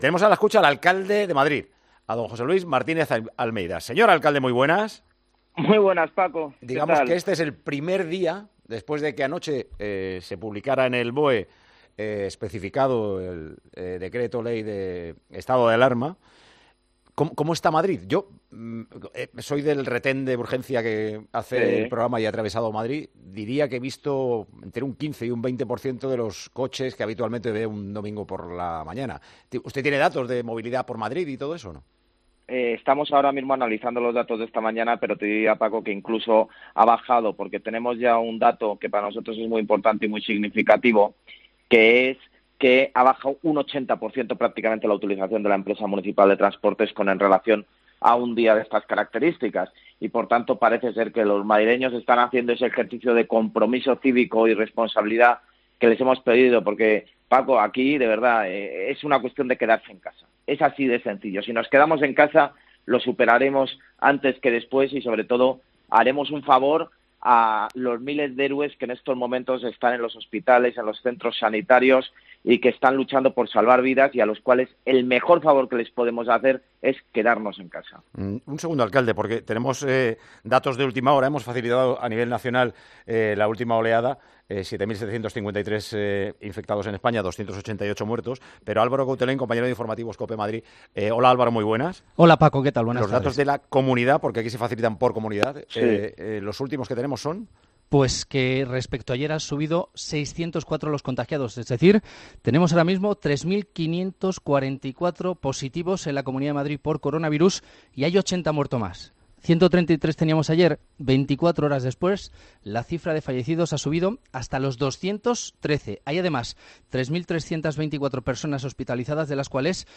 El alcalde de Madrid ha pasado por los micrófonos de Tiempo de Juego dejando alguna frase esperanzadora para el futuro